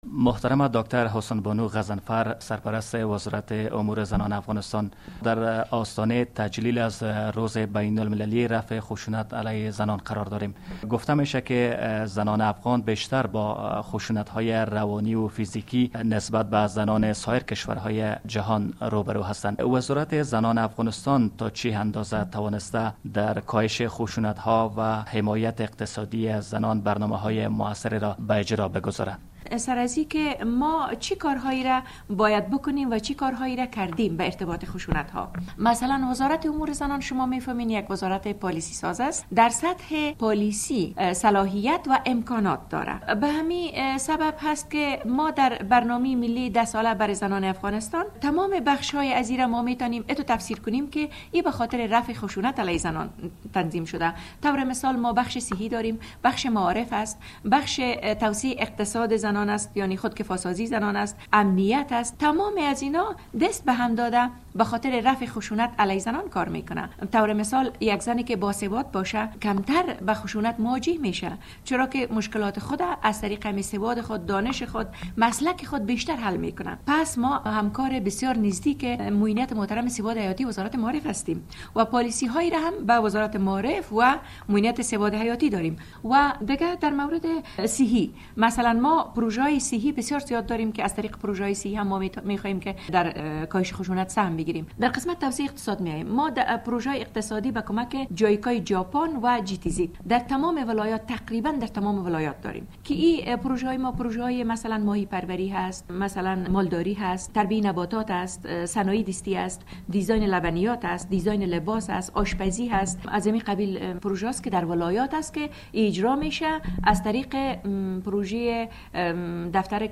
مصاحبه با وزیر زنان در مورد خشونت علیهء زنان